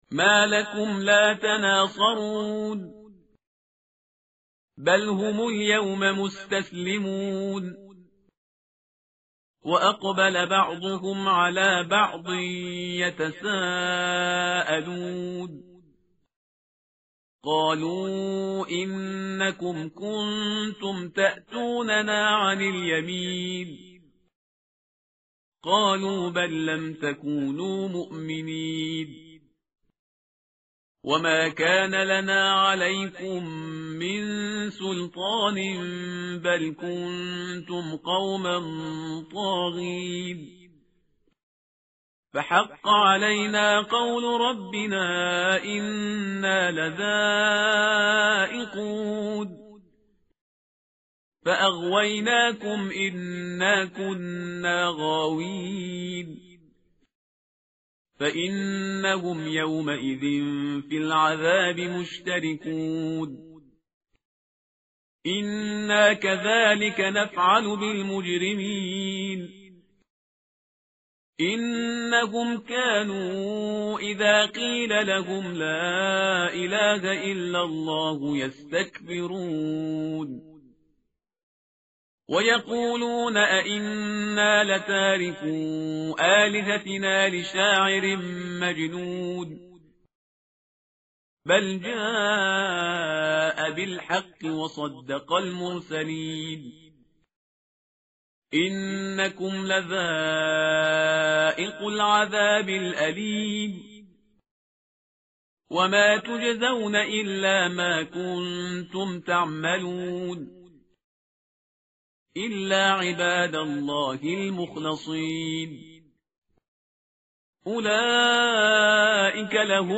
متن قرآن همراه باتلاوت قرآن و ترجمه
tartil_parhizgar_page_447.mp3